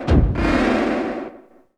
Index of /90_sSampleCDs/E-MU Producer Series Vol. 3 – Hollywood Sound Effects/Science Fiction/Columns
SYNTH DOOR-R.wav